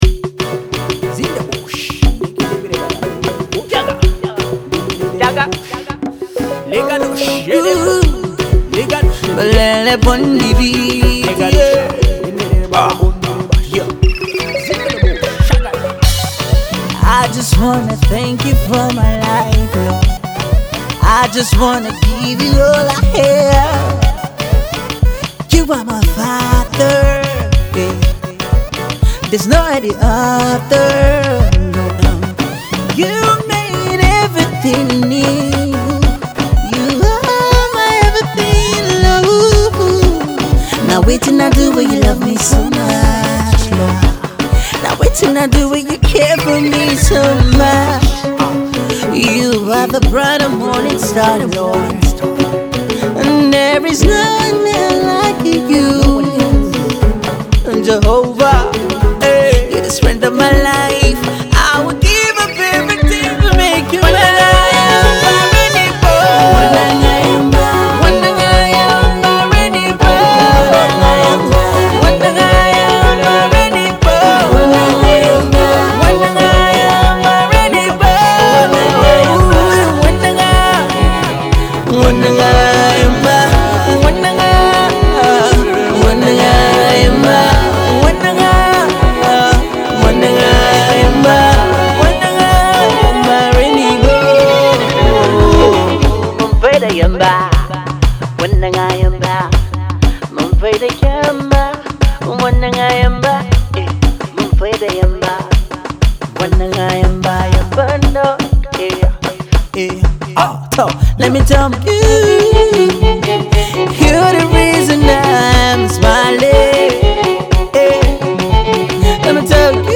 inspiring song
Gospel
His music is best described as spirit life Sound.